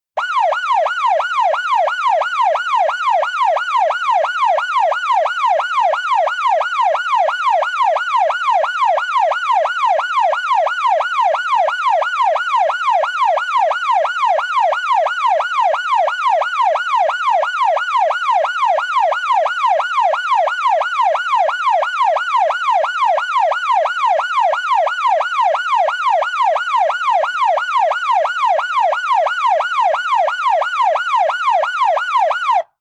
Yield to Emergency Responder Yelp .mp3 {veryfast: warble}
Yield_to_Emergency_Responder_Yelp.mp3